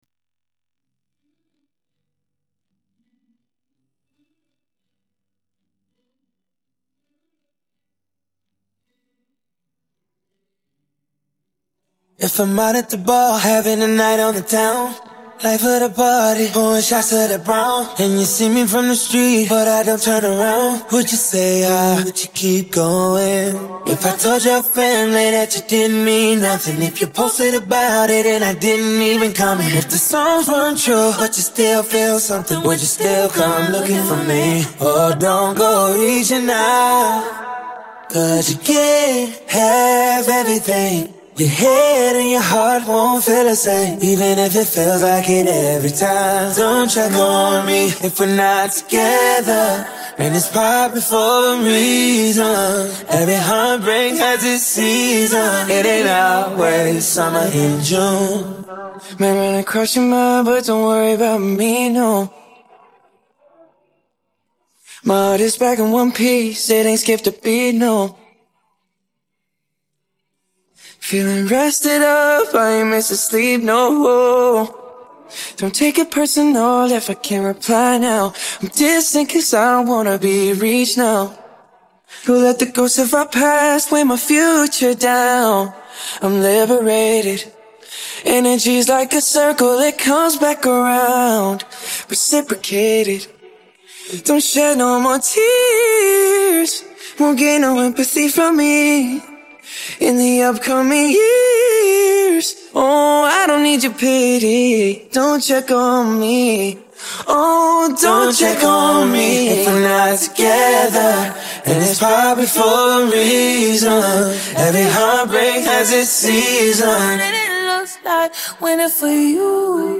Parte vocal